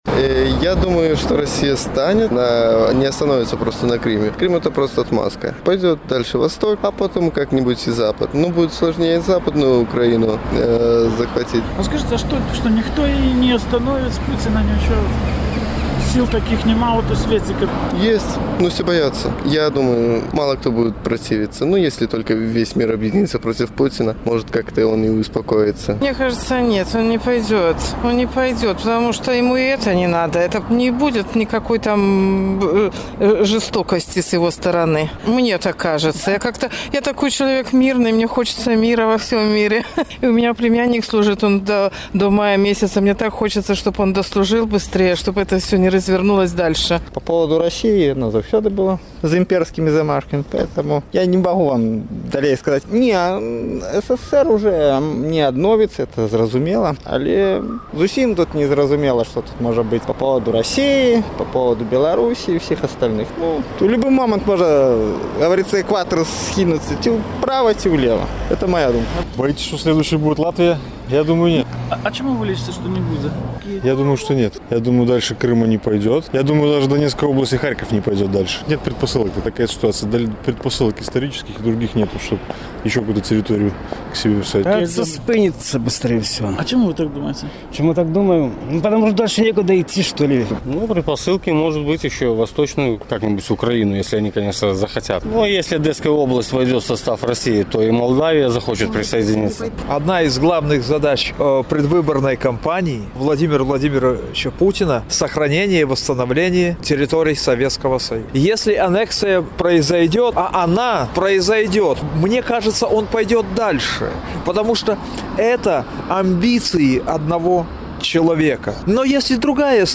Ці спыніцца Пуцін, калі яму дазволяць акупаваць і анэксаваць Крым? Якая краіна можа стаць наступнай ахвярай Расеі? З такімі пытаньнямі наш карэспандэнт зьвяртаўся да гарадзенцаў.